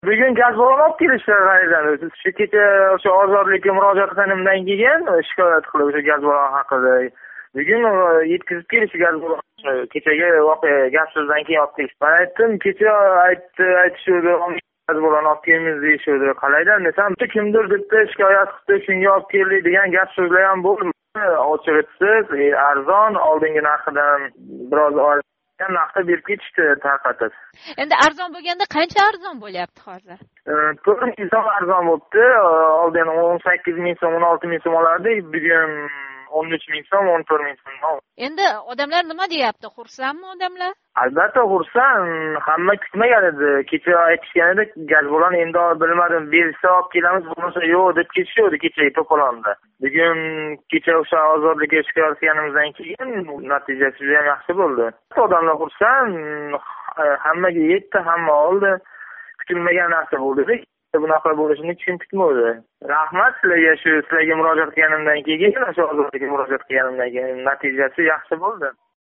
Озодликка бўлган ушбу шикоятнинг эртасига пунгонликлар яна радиога боғланиб, газ баллонлари олиб келингани ва арзон нархда аҳолига тарқатилгани ҳақида хабар қилишди:
Пунгонлик йигит: Озодликка шикоятдан сўнг газ баллонлари тарқатилди